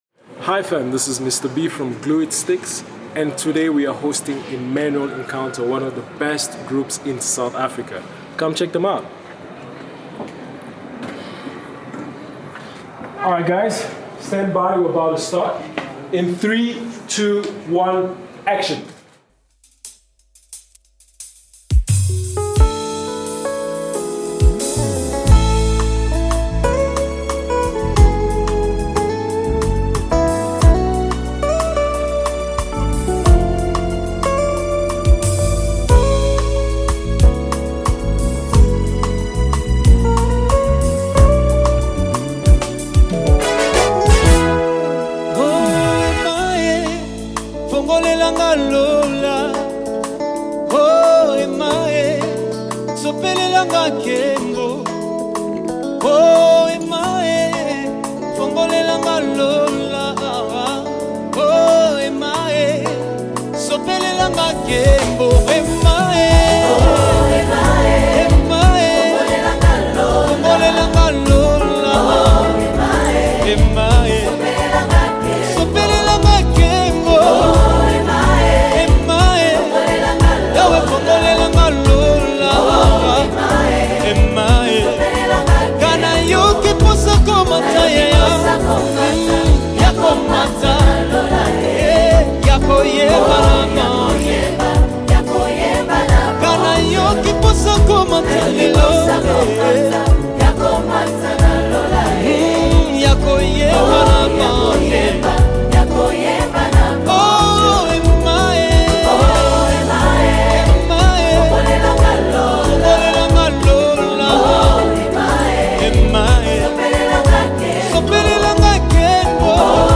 Gospel
praise worship